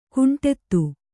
♪ kuṇṭettu